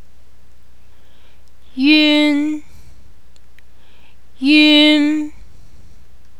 Vowels
Click a letter to hear Vowels!
vowelun..wav